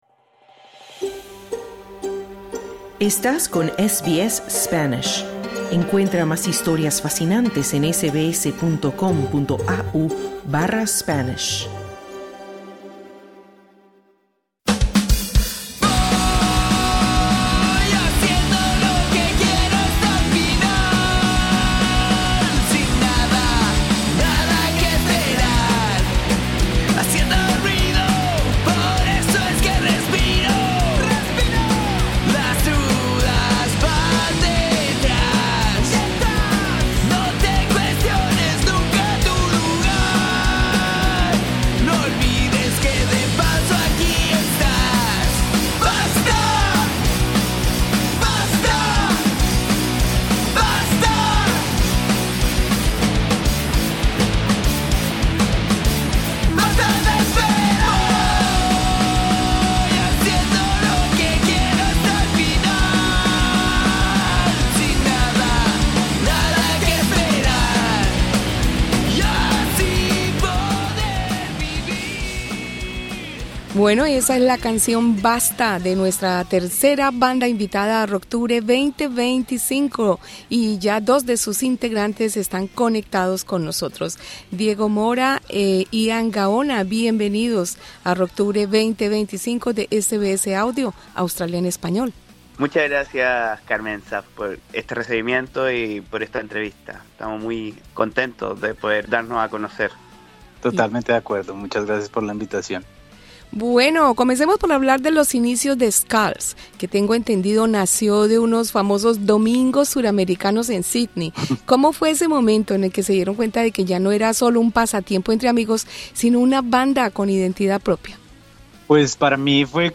Entrevista con integrantes de la banda basada en Sídney, Skulls, compuesta por un chileno, un colombiano y un australiano, quienes trabajan en sus primeras canciones originales, con la intención de lanzar pronto su primer EP. Skulls es una de las bandas invitadas a la tercera edición de Rocktubre, de SBS Audio.